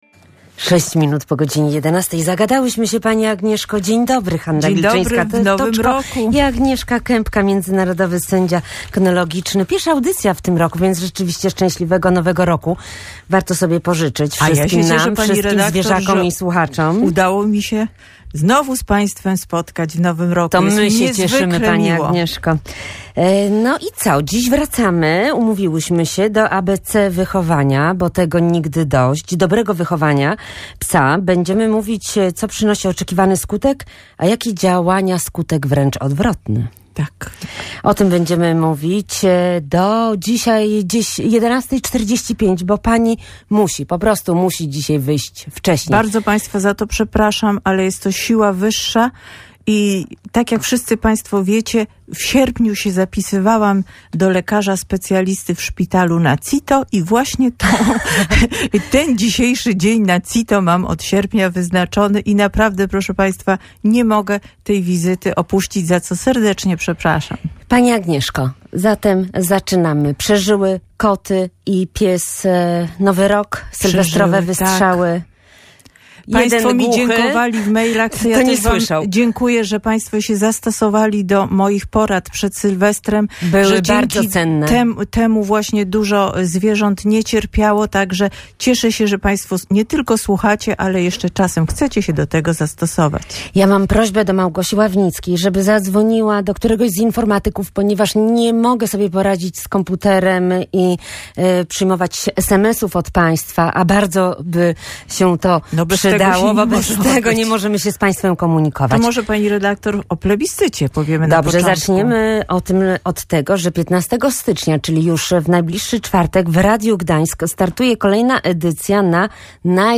sędzia kynologiczny i behawiorystka